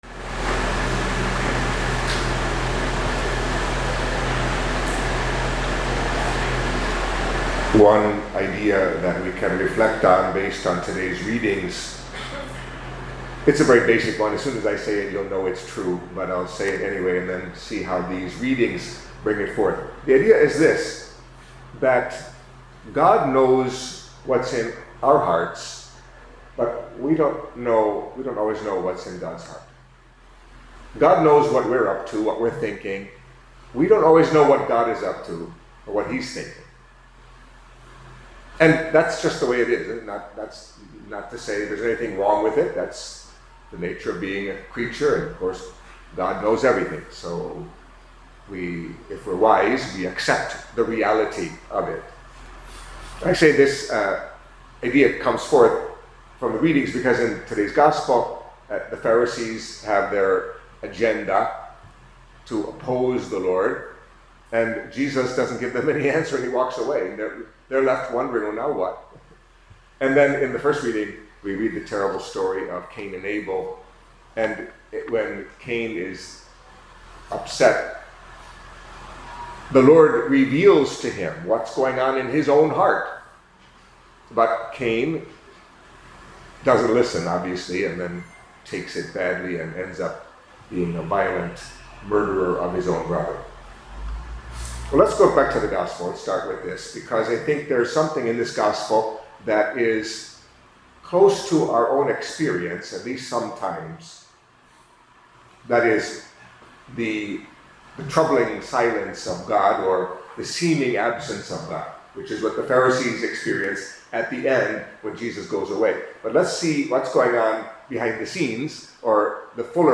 Catholic Mass homily for Monday of the Sixth Week in Ordinary Time